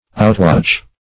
Outwatch \Out*watch"\, v. t. To exceed in watching.